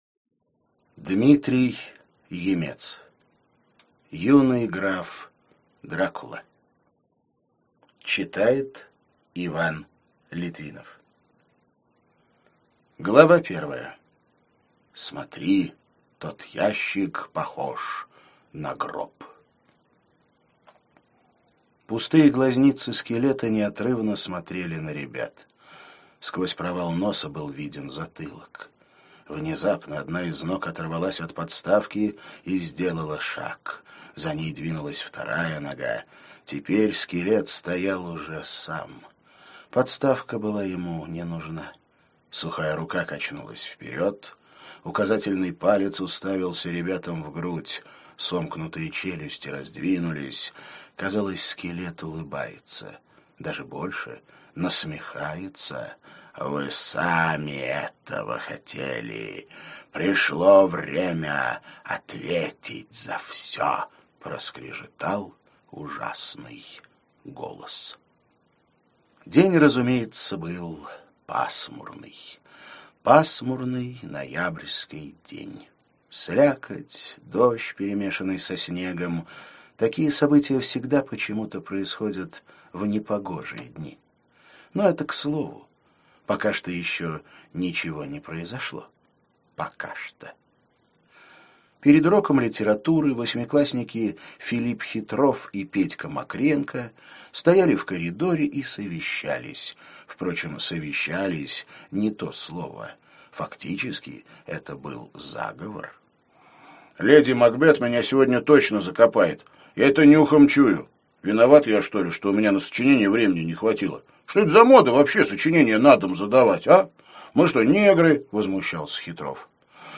Аудиокнига Юный граф Дракула | Библиотека аудиокниг